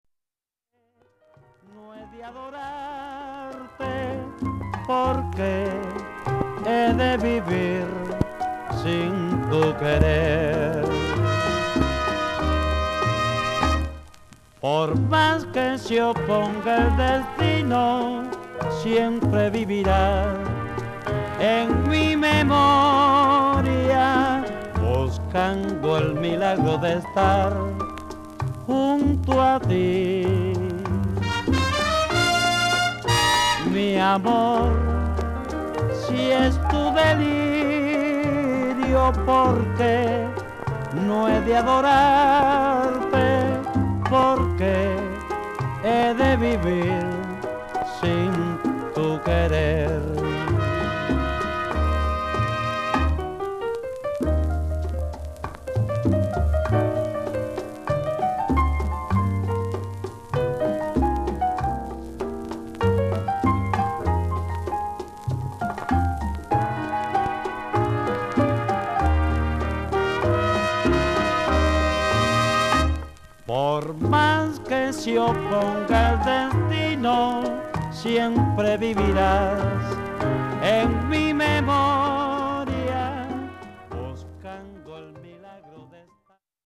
BOLERO